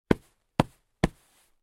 На этой странице собраны разнообразные звуки дивана: от скрипов старых пружин до мягкого шуршания обивки.
Стукаем ладошкой по пушистому дивану (звук)